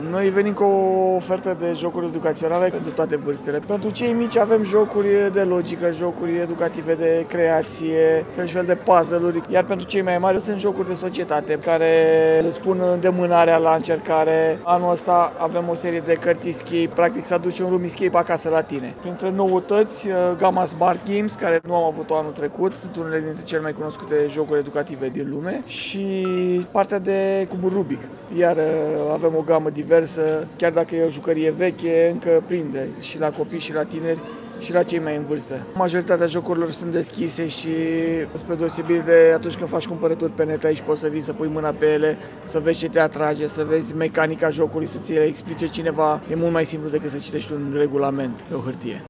La Craiova, Târgul de carte Gaudeamus, organizat de Radio România, în foaierul Teatrului Naţional, este în plină desfăşurare.